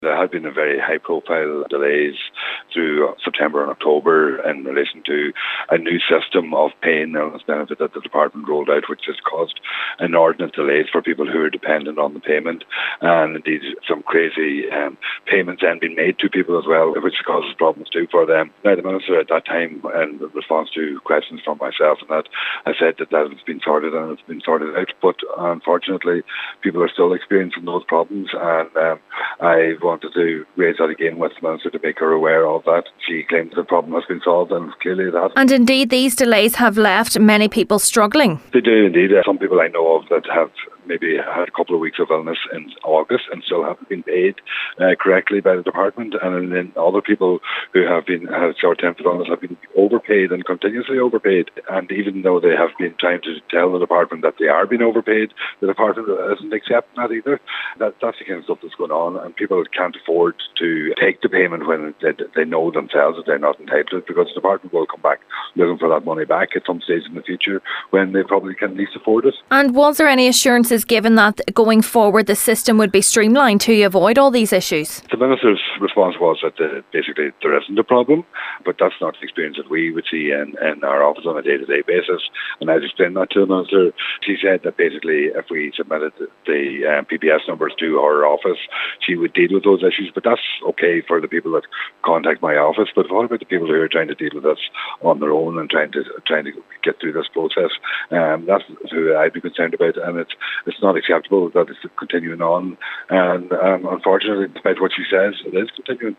In the Dail today, Deputy Pringle confronted Minister Regina Doherty on the continued delays affecting many people arising from a technological upgrade of the Illness Benefit system